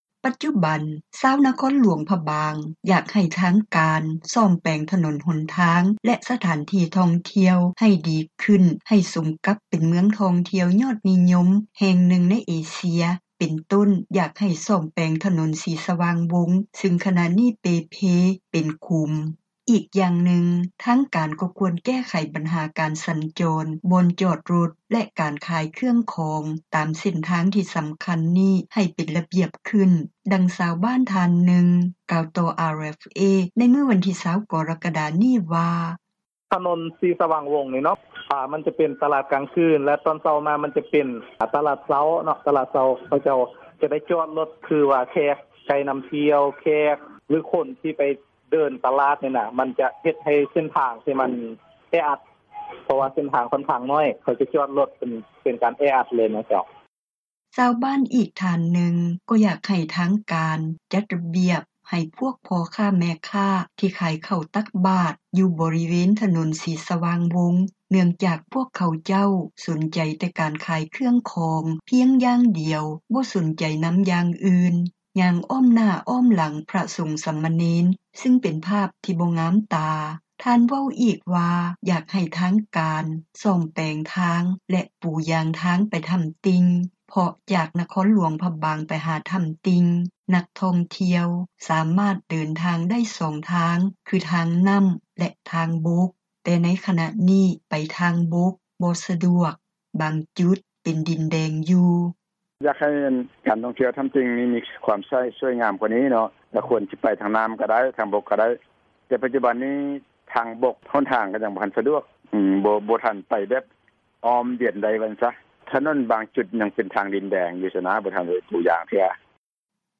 ດັ່ງຊາວບ້ານ ໃນນະຄອນຫຼວງພຣະບາງ ໄດ້ກ່າວຕໍ່ເອເຊັຽເສຣີ ໃນວັນທີ 20 ກໍຣະກະດາ ວ່າ: